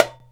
DUMBEK 5A.WAV